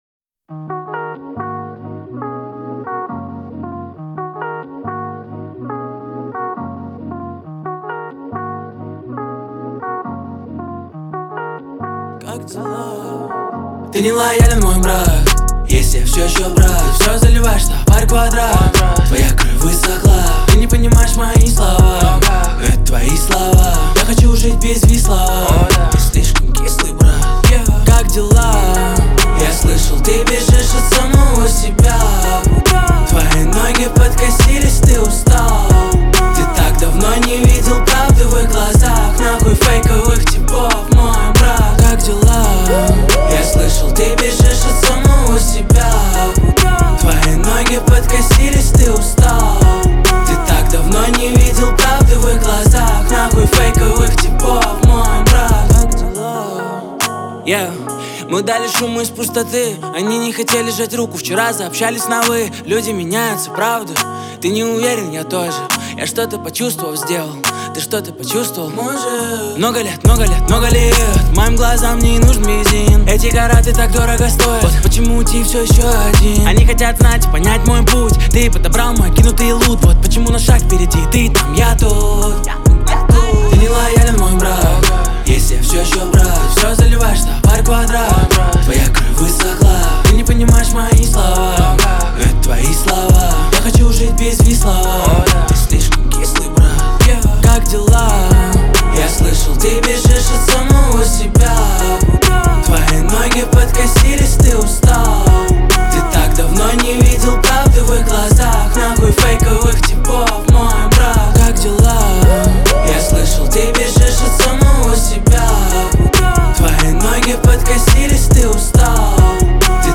мелодичный вокал
запоминающийся бит